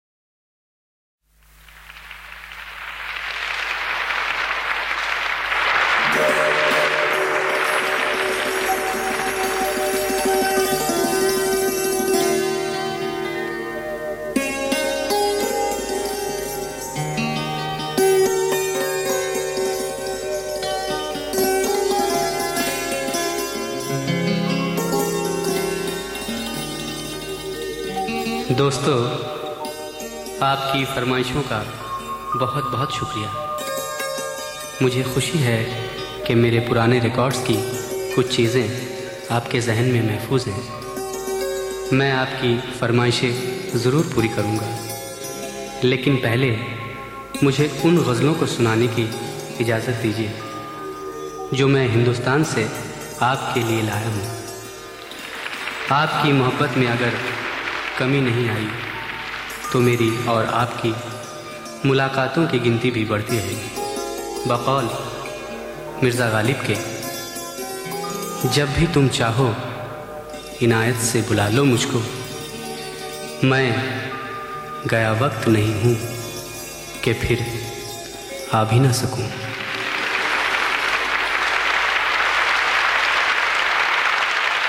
Ghazals
Live